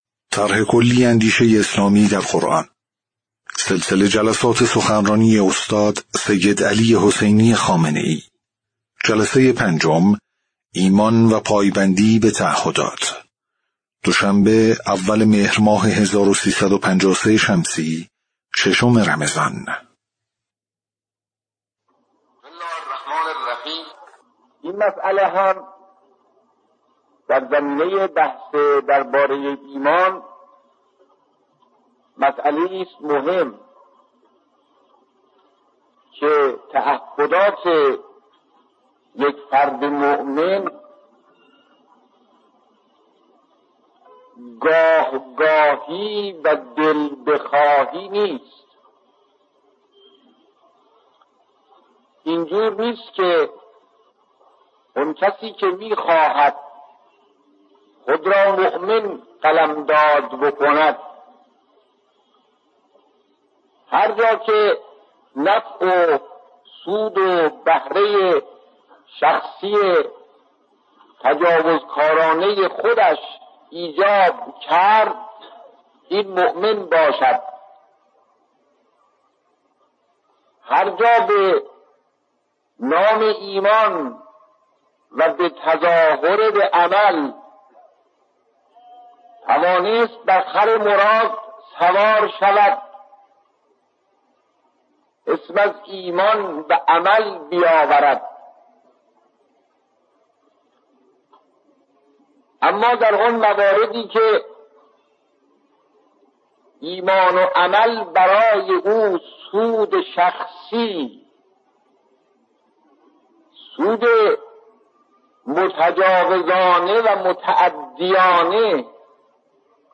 صوت/ جلسه‌ پنجم سخنرانی استاد سیدعلی‌ خامنه‌ای رمضان۱۳۵۳
سخنرانی قدیمی